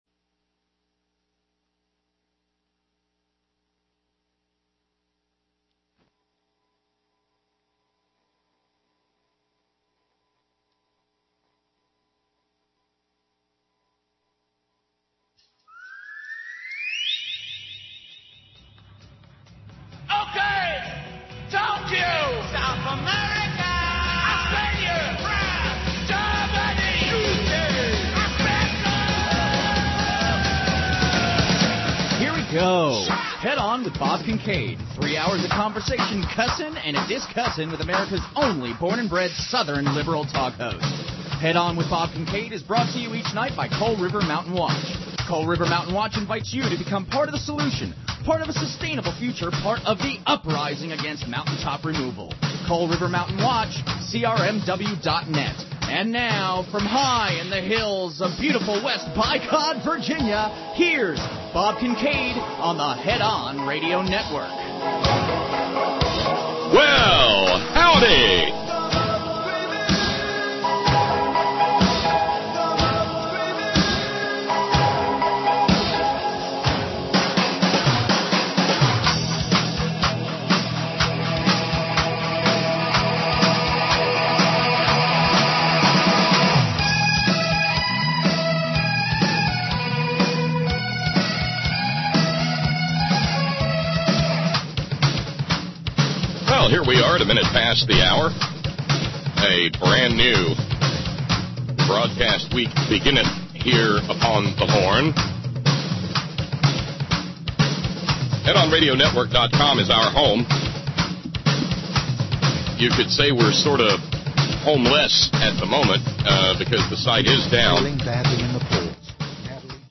United Mine Workers president Cecil Roberts spars with Chris Hayes